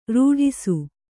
♪ rūḍhisu